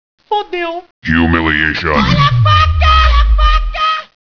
knife4.wav